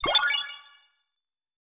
start_new_level.wav